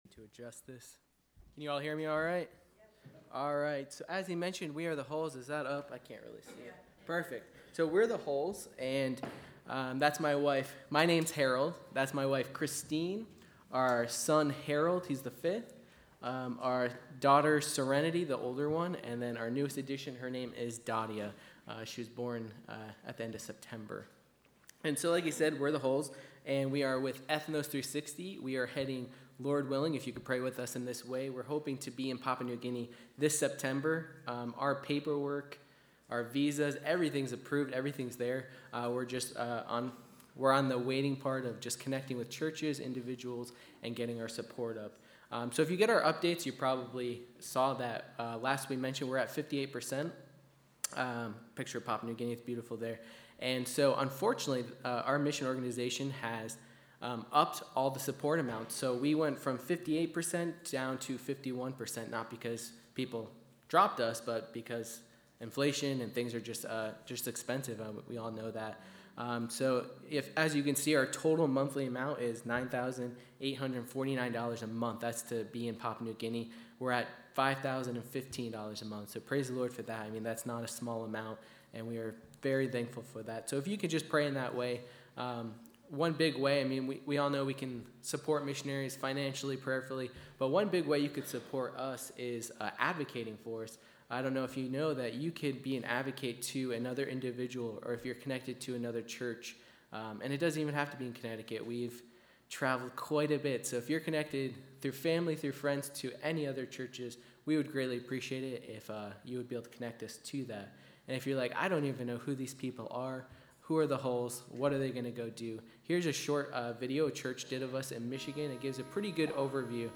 Listen to sermons by our pastor on various topics.
Guest Speaker